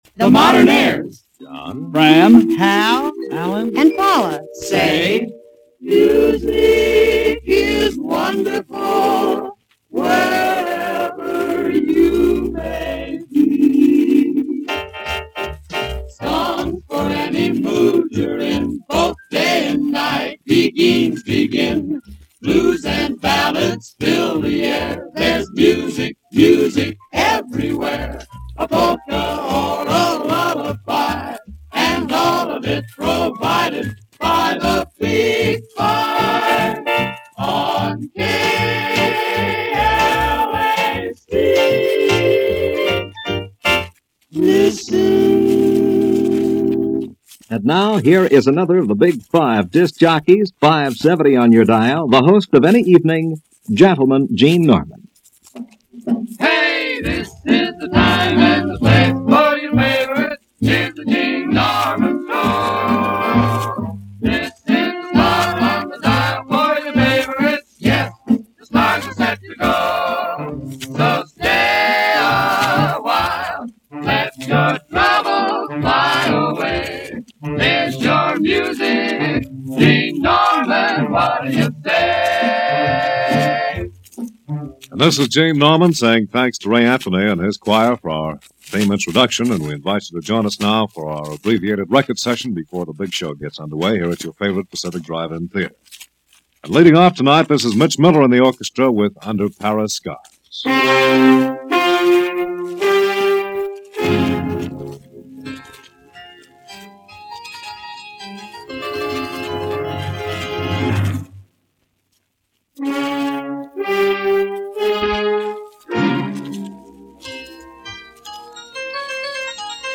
Before the first movie and before the second movie, at the intermission you got to hear perhaps your favorite disc jockey spinning new releases – adding the bonus of music to your Saturday night.